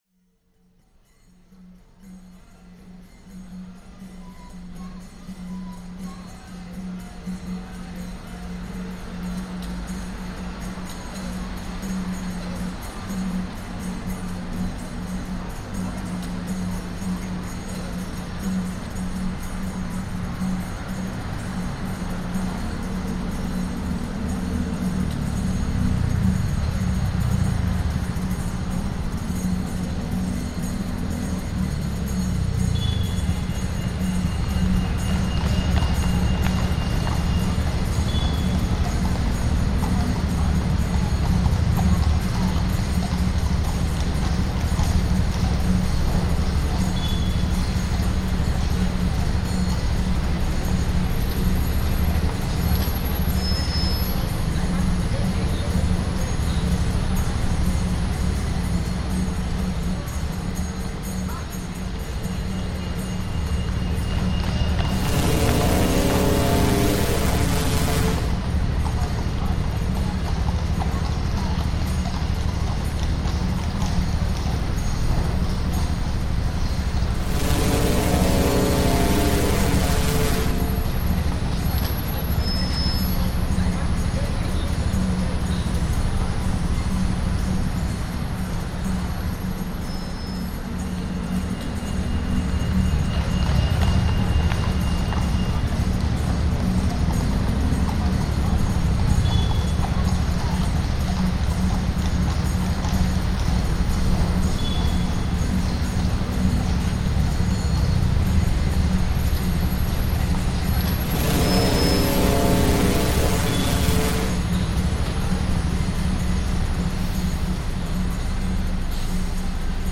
Oxford Circus, London lockdown sound reimagined